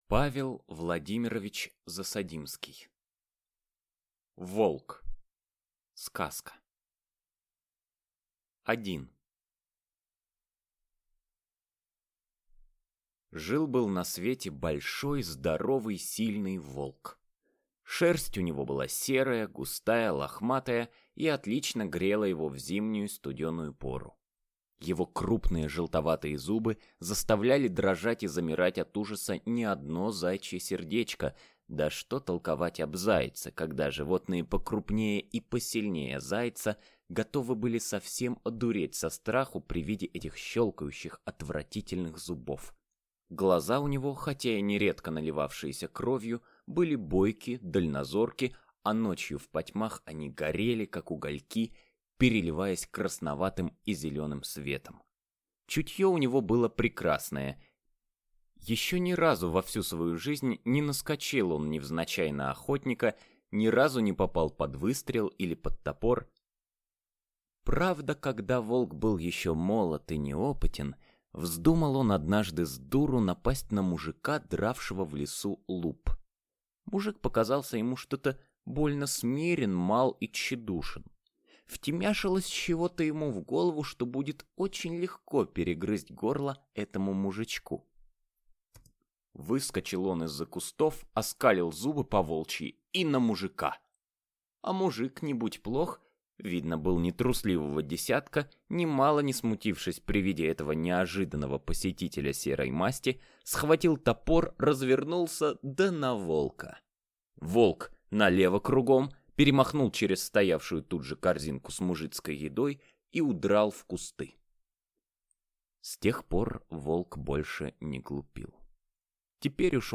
Аудиокнига Волк | Библиотека аудиокниг